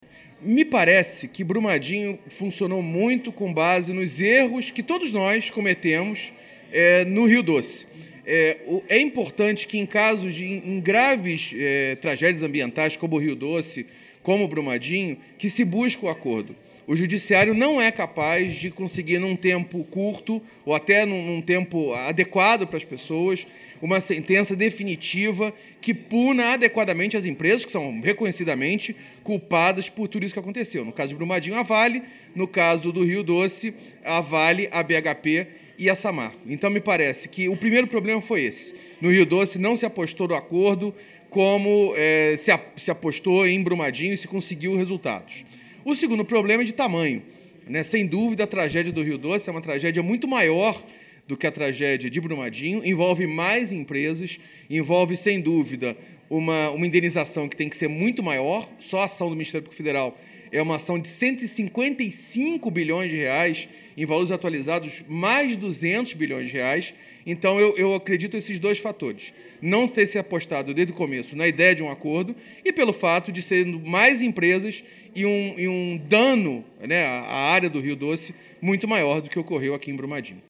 O Portal GRNEWS ouviu o procurador da República Carlos Bruno Ferreira da Silva, que atua no Ministério Público Federal – um dos signatários do acordo feito com a Vale para reparação de Brumadinho – e coordena também a Força-Tarefa Rio Doce-Brumadinho.